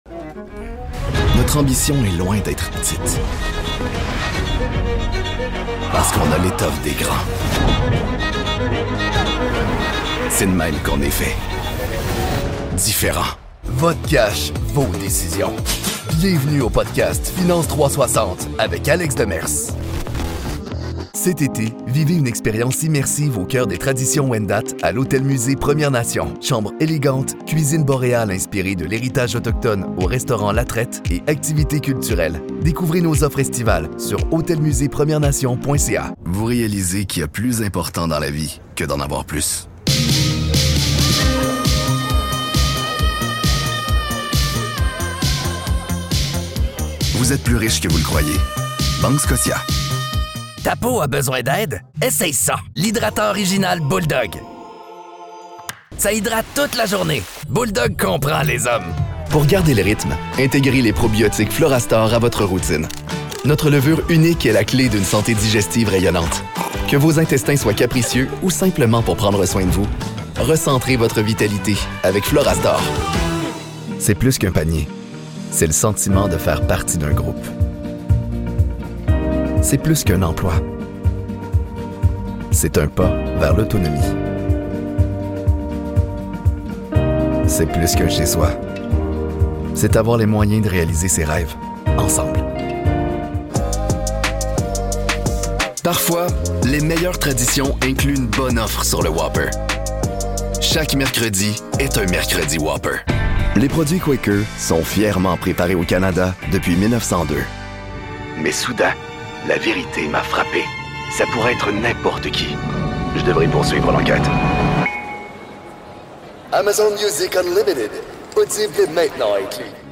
British Radio, TV Commercial Voice Overs Talent
Never any Artificial Voices used, unlike other sites.
Adult (30-50) | Yng Adult (18-29)